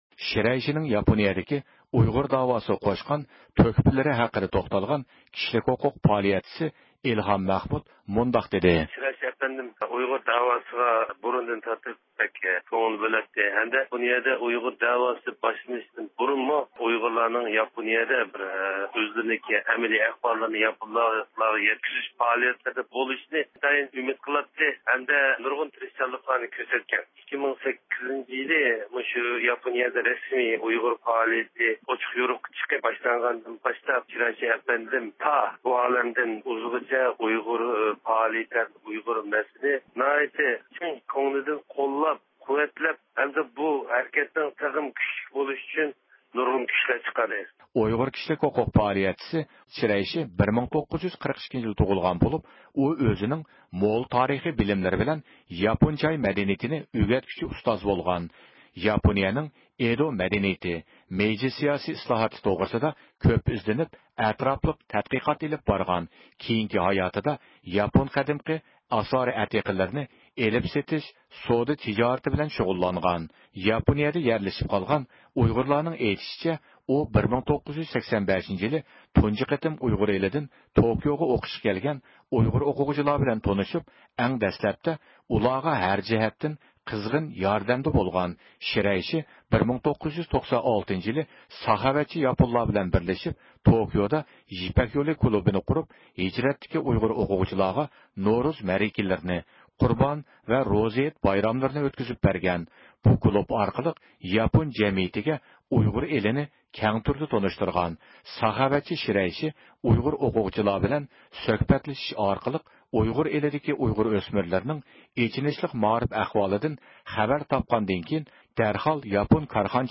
بىز بۇ ھەقتە تولۇق مەلۇماتقا ئېرىشىش ئۈچۈن نەق مەيدانغا تېلېفون قىلىپ دۇنيا ئۇيغۇر قۇرۇلتىيى رەئىسى رابىيە قادىر خانىم بىلەن سۆھبەت ئېلىپ باردۇق.